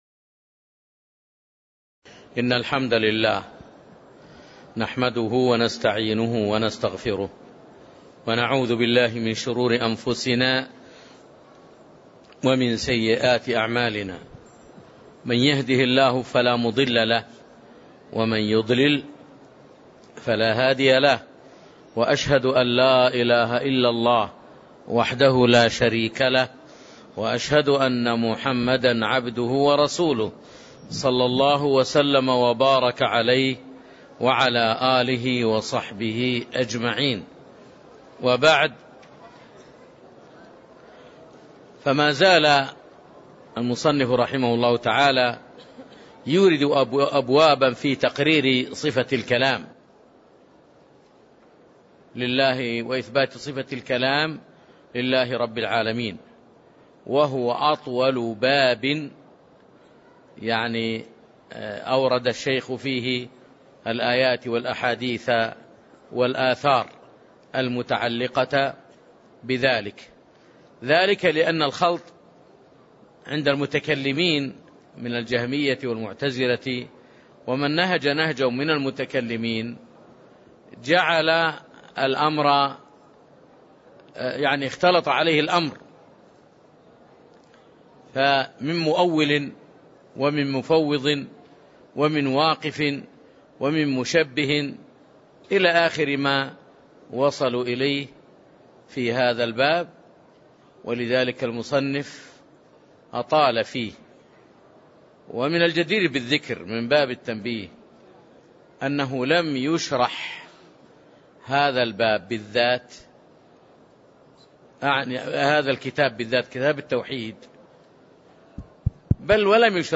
تاريخ النشر ٤ صفر ١٤٣٦ هـ المكان: المسجد النبوي الشيخ